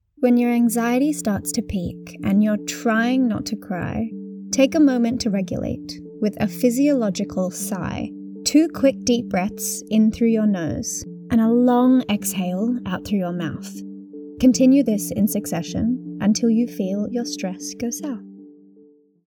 Female
My vocal quality is bright, authentic and engaging.
I record in a sound treated studio at home using a Rode NT USB+ microphone.
Natural Speak
Breathing Technique
1208natural__sigh__sound_check.mp3